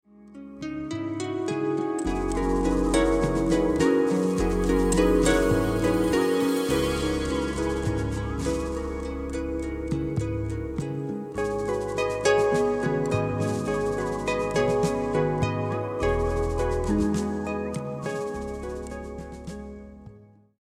electracoustic pedal harp, gu-cheng & more...
Recorded and mixed at the Sinus Studios, Bern, Switzerland